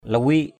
/la-wi:ʔ/ (cv.) liwik l{w{K (t.) lâu, lâu ngày = longtemps. last, for a long time. lawik harei lw{K hr] lâu ngày = pendant longtemps. lawik malam lw{K ml’ khuya = tard....
lawik.mp3